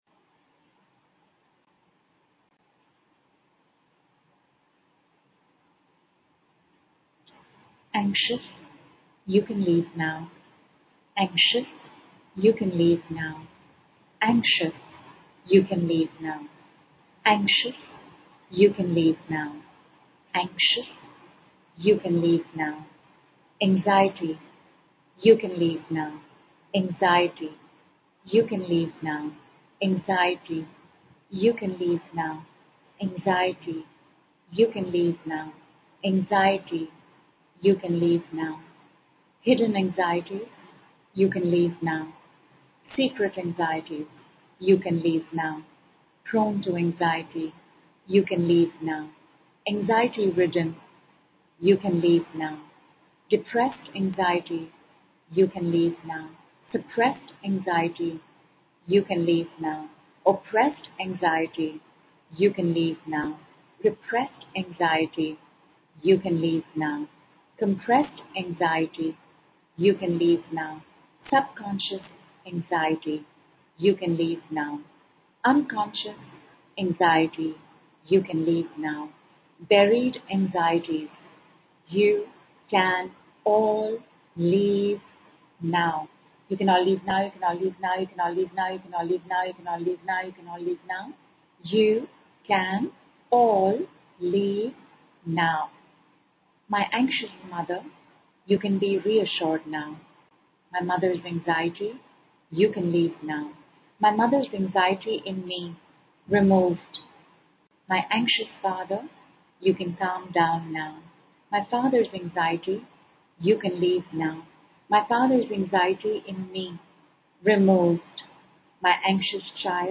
They are cosmic commands spoken out loud that employ the universe to bring you what you want in the shortest time possible.